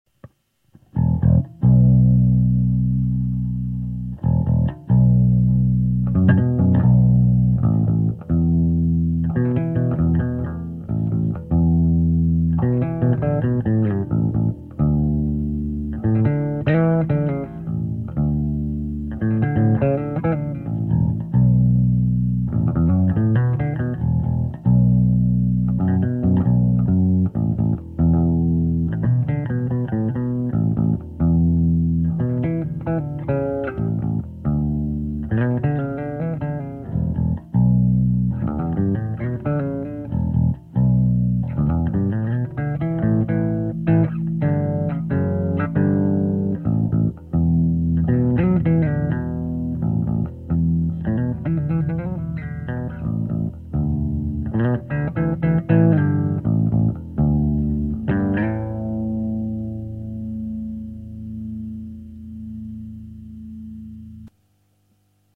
voila trois samples du preamp enregistrés avec le fostex , en reprise micro sur un 15" .aucunes retouche , sauf une minuscule pointe de reverbe avec le fostex . c'est un exemple de reglage du preamp , de memoire basse 3/4 , mid 1/2 , treble 1/4 . bright en position milieu ( son naturel ) on peut donc l'aloudir ou le rendre plus brillant .
canal disto : idem pour l'egalo gain 1/4 pour disto 1 et 1/2 pour disto 2 . bright en position milieu ( son naturel ) on peut donc l'aloudir ou le rendre plus brillant .
basse active shecter , preamp lampes , dagone 480 .
repris avec un micro akg d11 et un E906 , sur le fostex mr8 MKII .
sample_bass_disto1.MP3